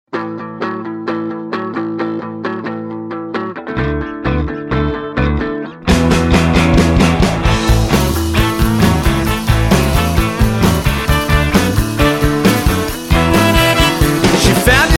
本作品内容为安静感人动听背景音乐
该作品音质清晰、流畅